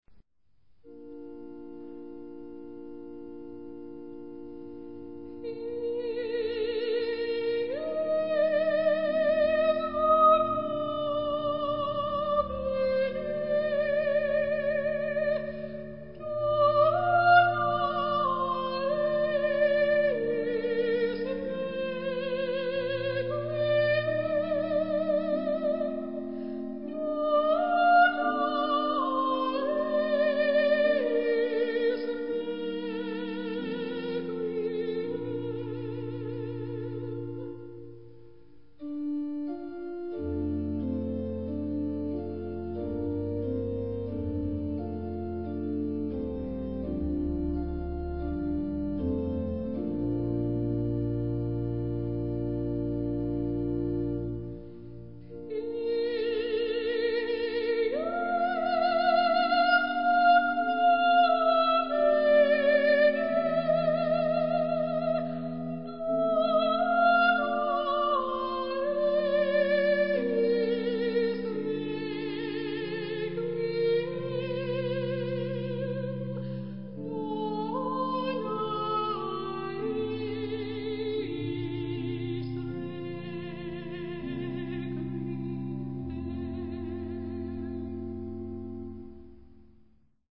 Moi je prendrais l'aria pour soprano "Pie Jesu" du Requiem en Ré mineur de Gabriel Fauré.